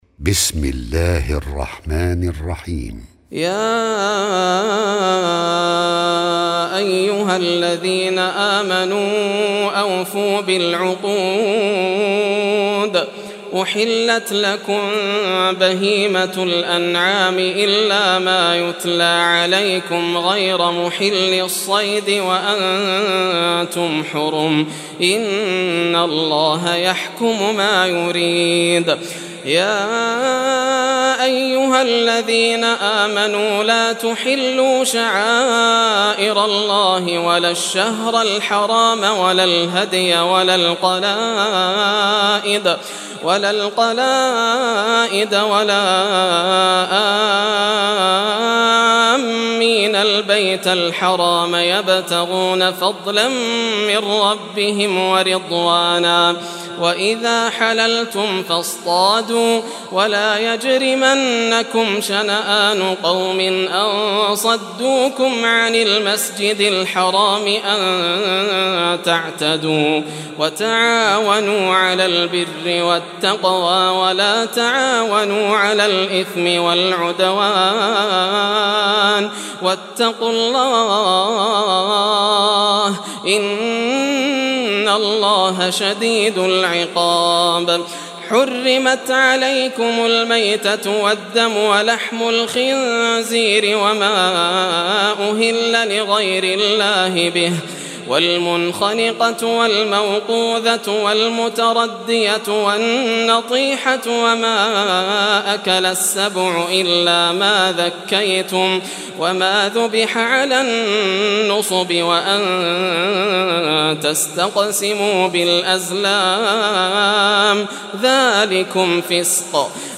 Surah Al-Maidah Recitation by Yasser al Dosari
Surah Al-Maidah, listen or play online mp3 tilawat / recitation in Arabic in the beautiful voice of Sheikh Yasser al Dosari.